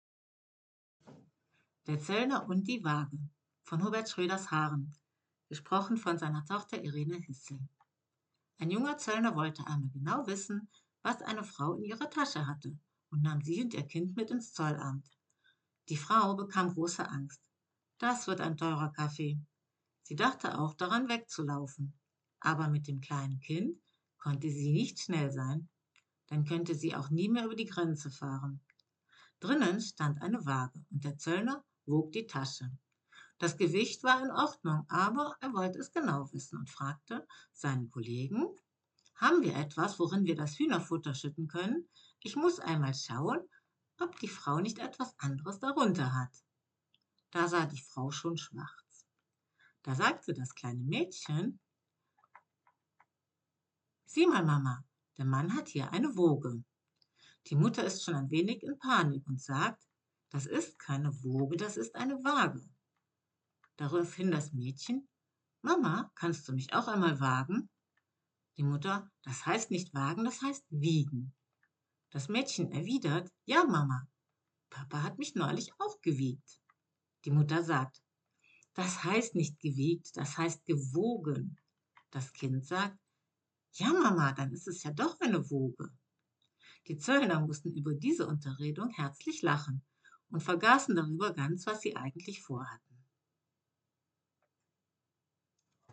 Text hochdeutsch
Geschichte